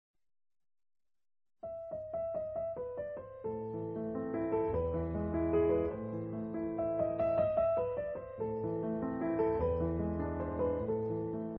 to mono